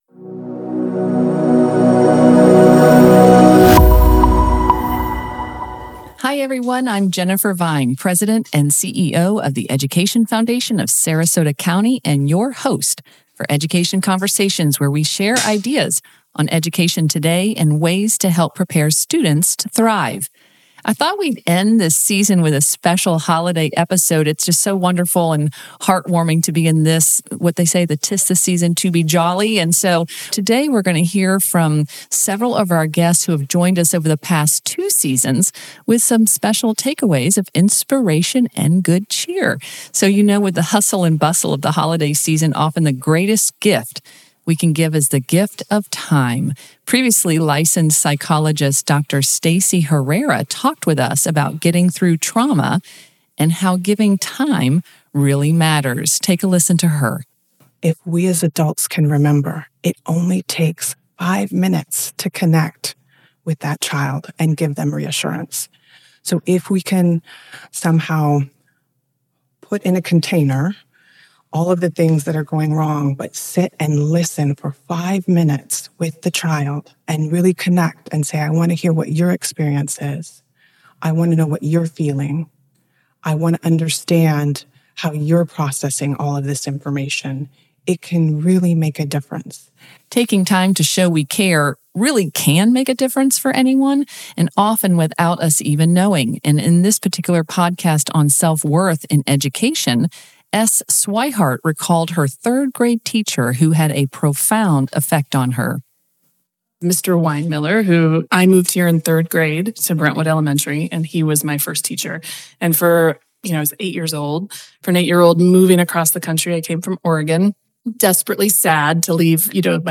Enjoy a special good cheer episode with this compilation of positive and inspiring clips from our guests over the past two seasons.